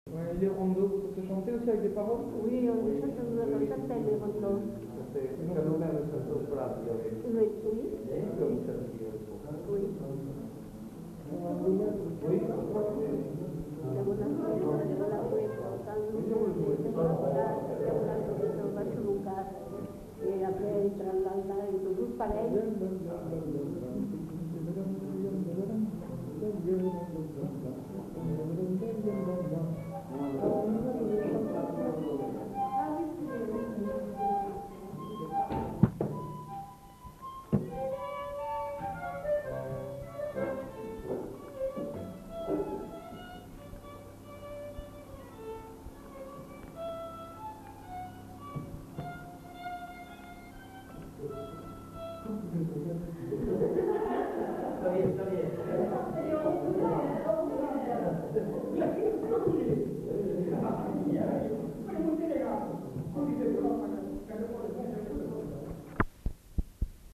Aire culturelle : Néracais
Lieu : Moncaut
Genre : chant
Effectif : 1
Type de voix : voix de femme
Production du son : parlé
Danse : rondeau